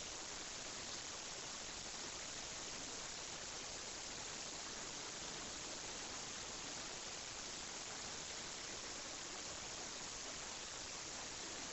Between 4200Hz and 8100Hz you see an increase in volume, which is probably where a lot of the hiss comes from. Anything above 8130Hz is high frequency crackle.
noise before noise reduction.wav